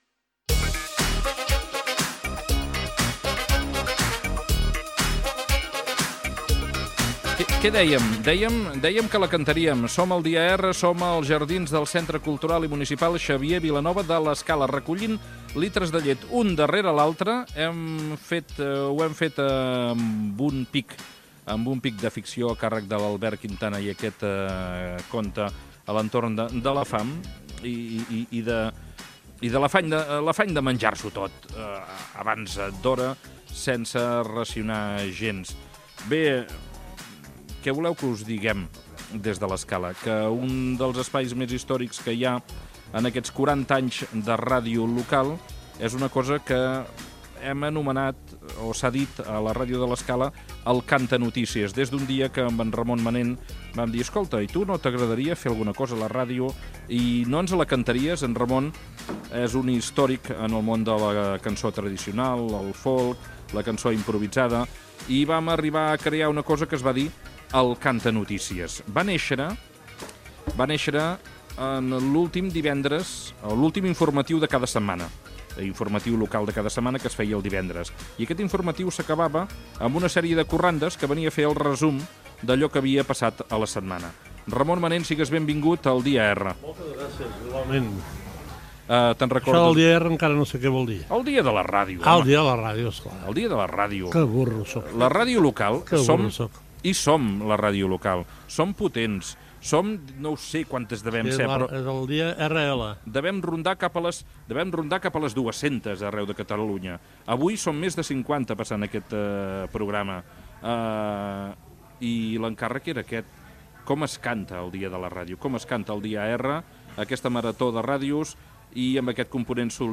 Des dels jardins Xavier Vilanova de l'Escala.
Entreteniment